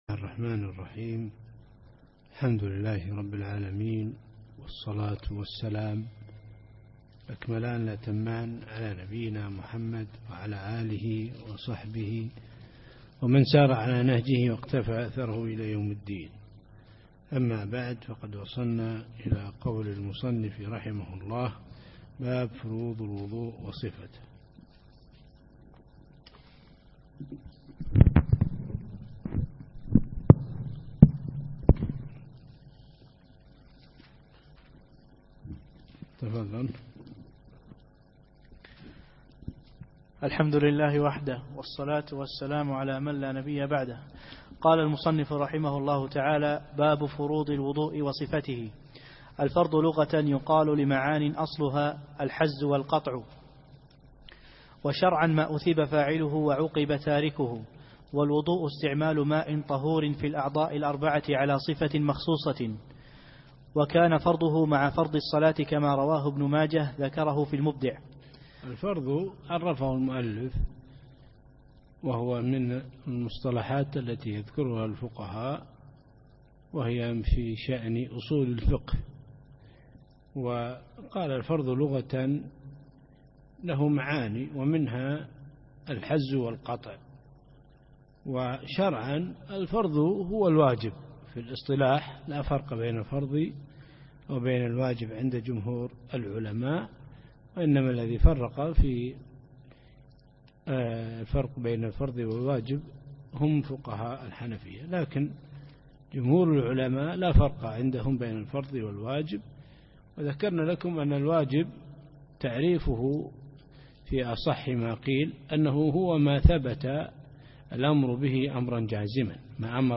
الدرس السادس: باب فروض الوضوء وصفته